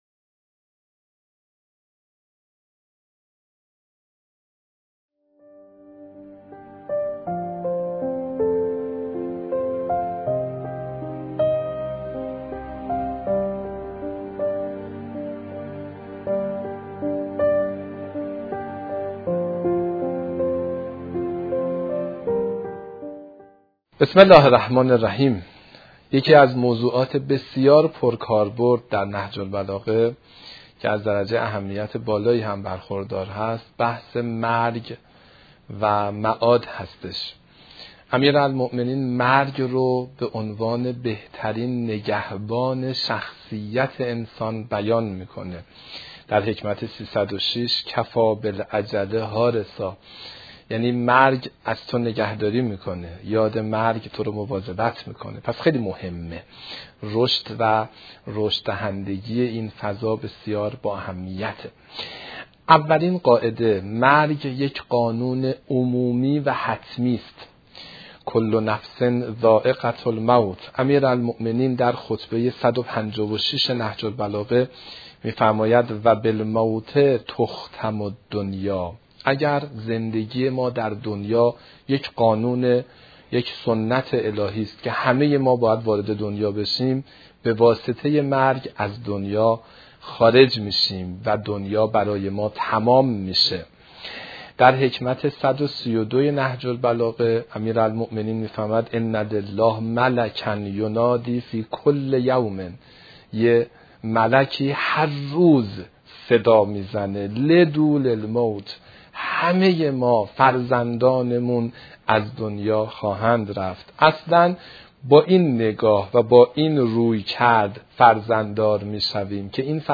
وعظ و اندرز